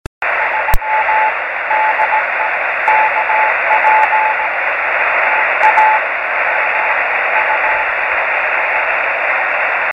Grabaciones Rebote Lunar de KP4AO,
Radiotelescopio del Observatorio de Arecibo en Puerto Rico
Equipo: Yaesu FT-817.
Antena: Yagi 9 elementos para 432 MHz en boom de madera de 1 m de largo.
3-cw.mp3